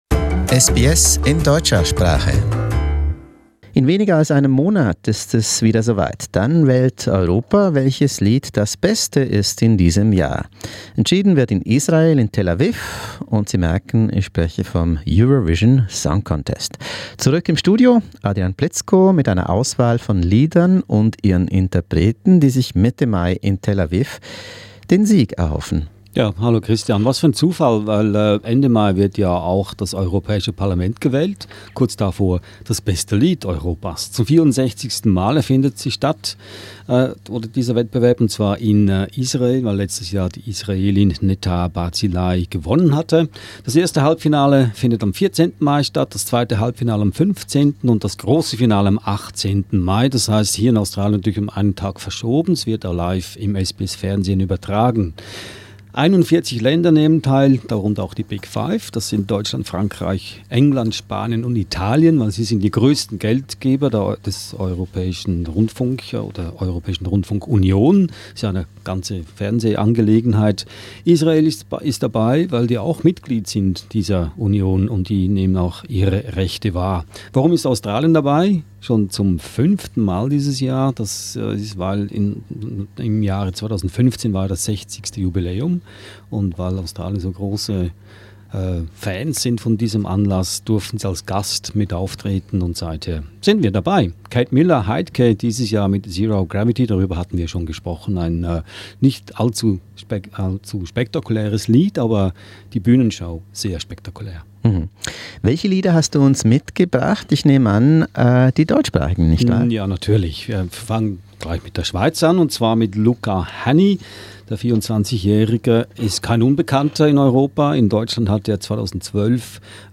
How promising are the entries from Switzerland, Austria and Germany this time 'round? Listen to our lively studio talk to find out more.